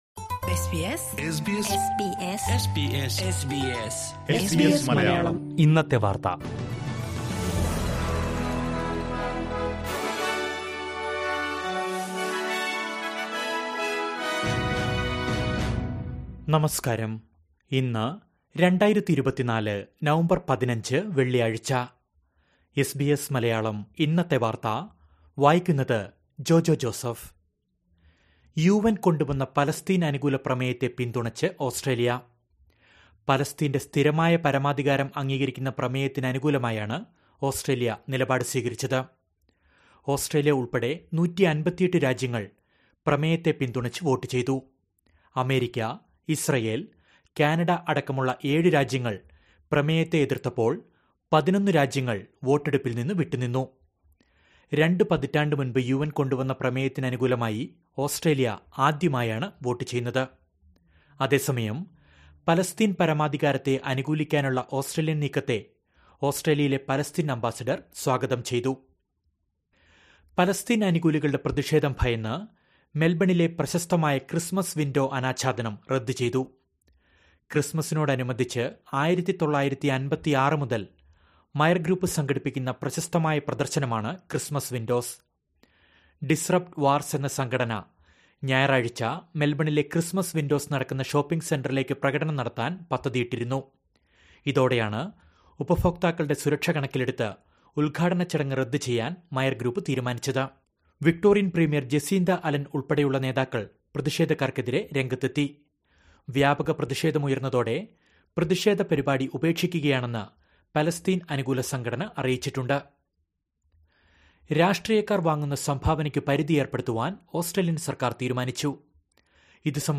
2024 നവംബർ 15ലെ ഓസ്ട്രേലിയയിലെ ഏറ്റവും പ്രധാന വാർത്തകൾ കേൾക്കാം...